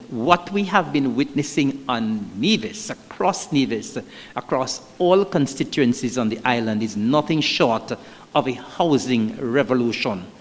The announcement was revealed at a Town Hall Meeting at the Hanley’s Road Community Centre on July 10th.